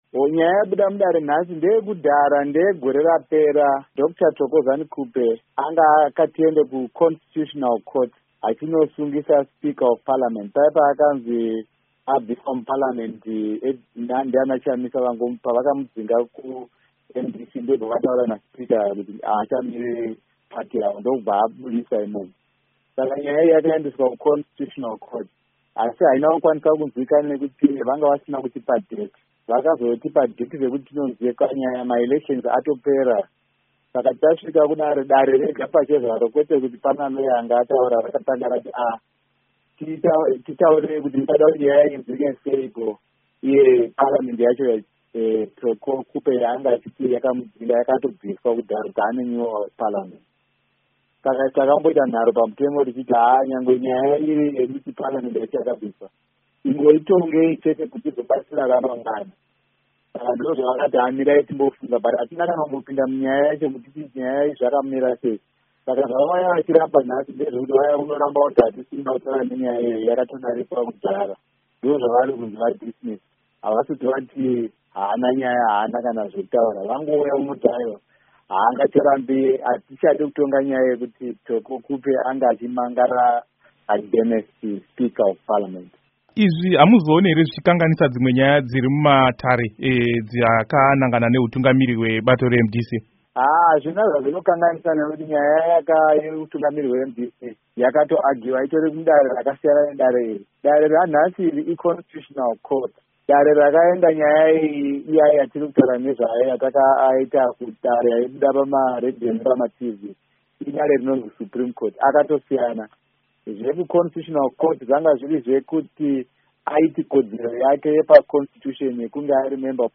Hurukuro naMuzvinafundo Lovemore Madhuku